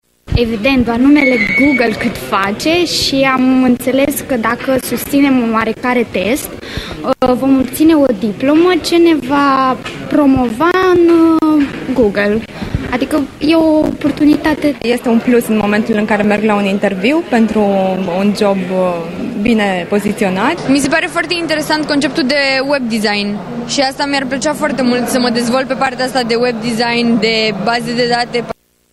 Cei prezenţi la curs au spus că îşi doresc să se perfecţioneze pentru a obţine mai uşor un loc de muncă bine plătit sau pentru a putea studia în străinătate: